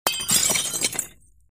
ガラスが割れた時の衝撃音。